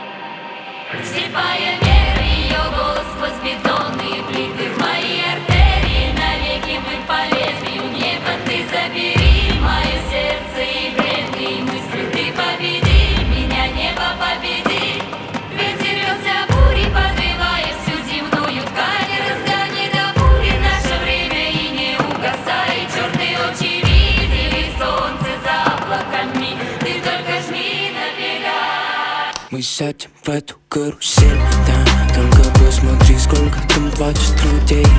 Жанр: Поп / Инди / Русские
# Indie Pop